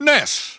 The announcer saying Ness' name in English and Japanese releases of Super Smash Bros. Brawl.
Ness_English_Announcer_SSBB.wav